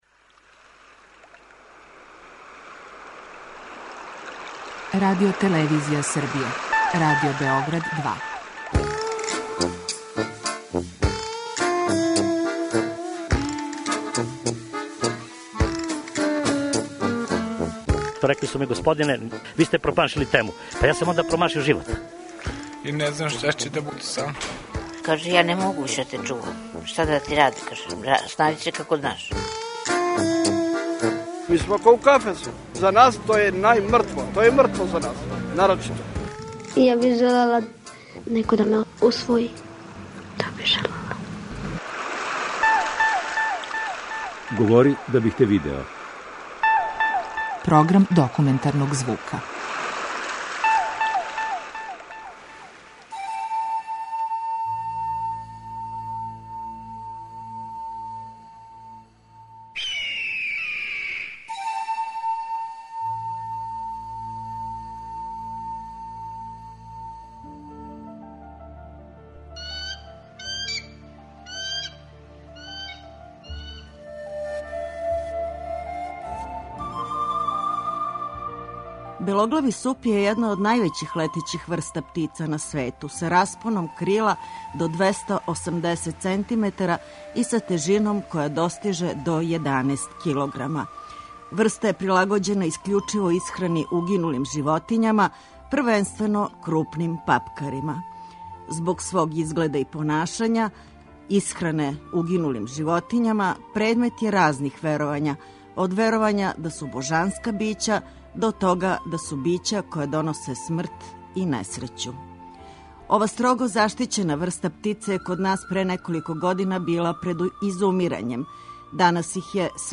Документарни програм: Заборављени занати - воскар